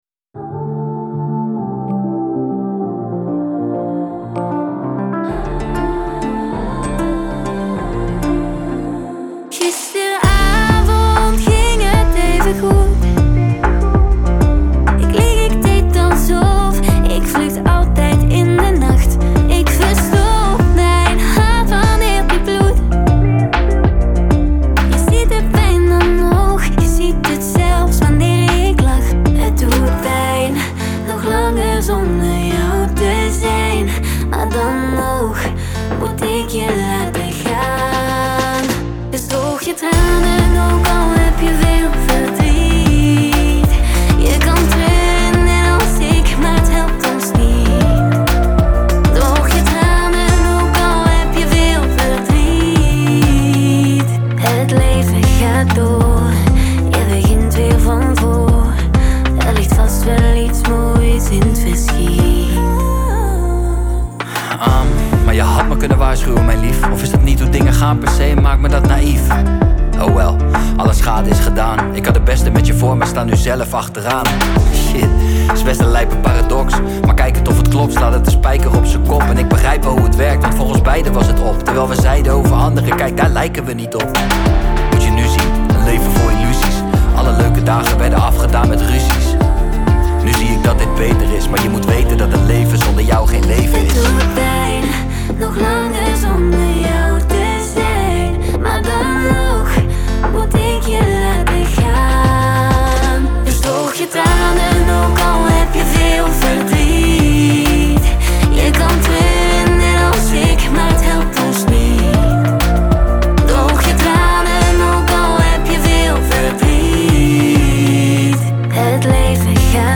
это энергичная поп-музыка